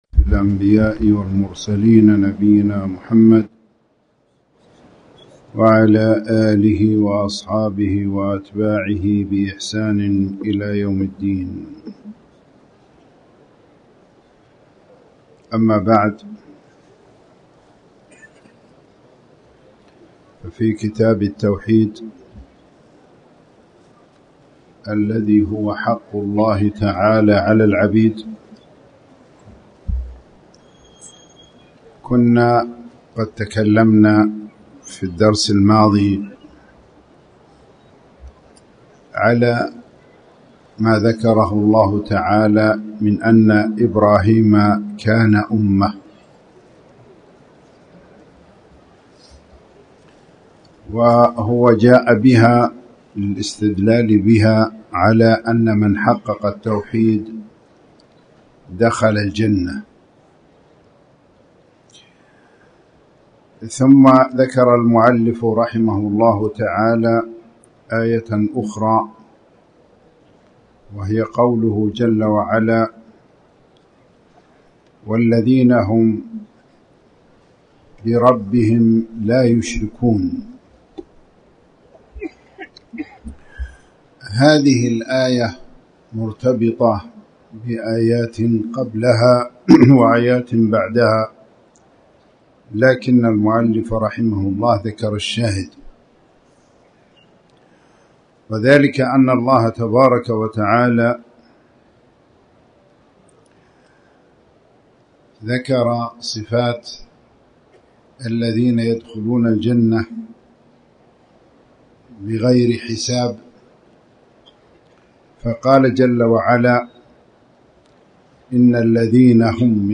تاريخ النشر ٢٢ ربيع الأول ١٤٣٩ هـ المكان: المسجد الحرام الشيخ